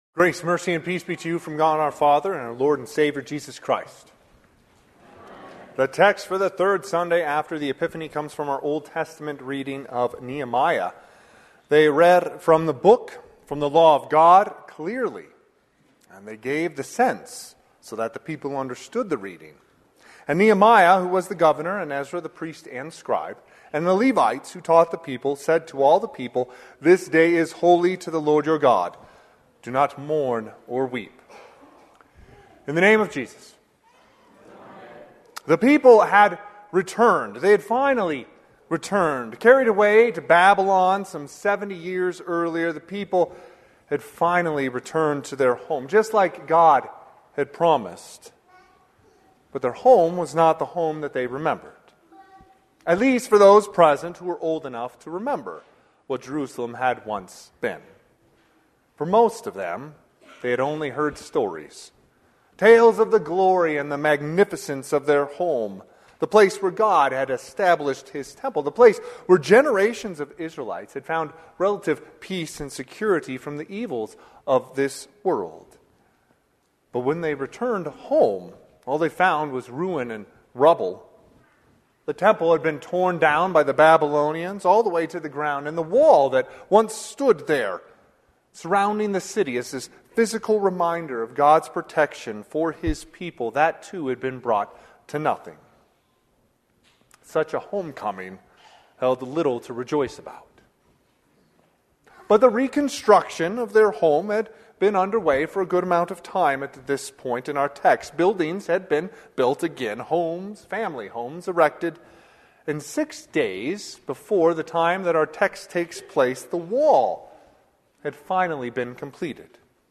Sermon - 1/26/2025 - Wheat Ridge Lutheran Church, Wheat Ridge, Colorado